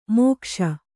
♪ mōkṣa